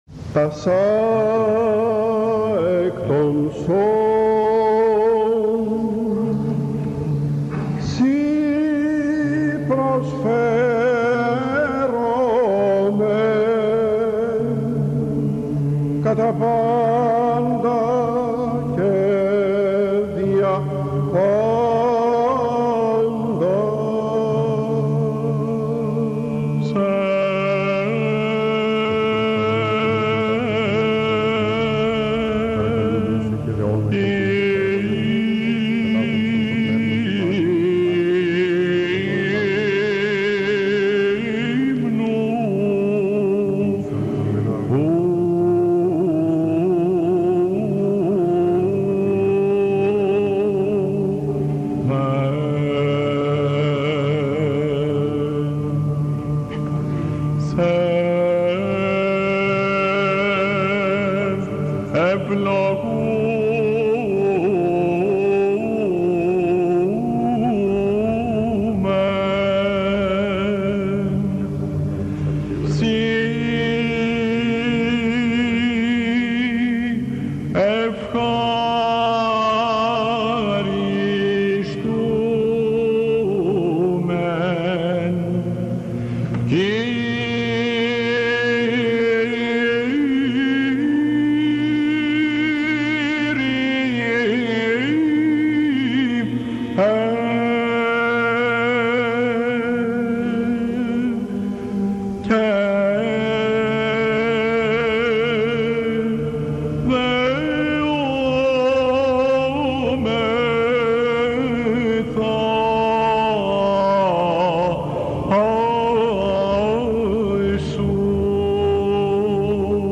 ΕΚΚΛΗΣΙΑΣΤΙΚΑ
"Σε Υμνούμεν" - Ιούνιος 1980 Αχειροποίητος Θεσ/νίκης.